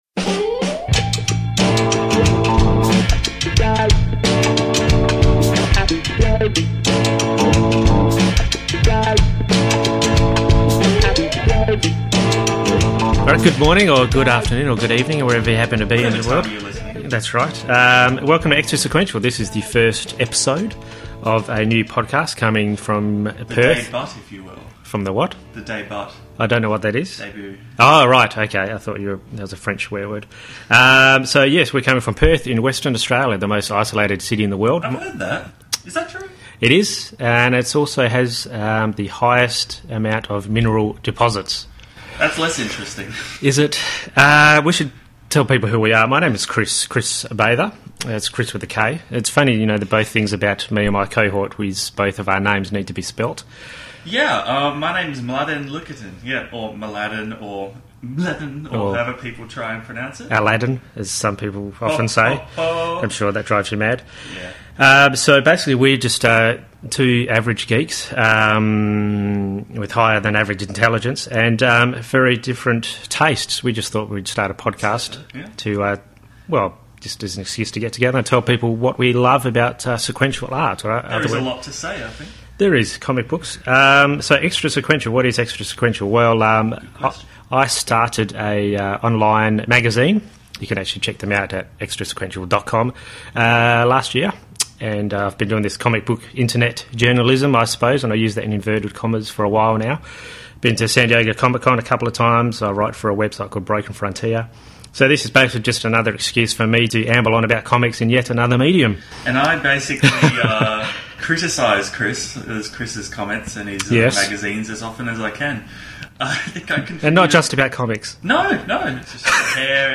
We’ll be chatting it up weekly, and like the Extra Sequential magazine our aim is to make diehard fanboys and girls, and comic book newbies feel at home, so fear not if you’re not a regular comics reader!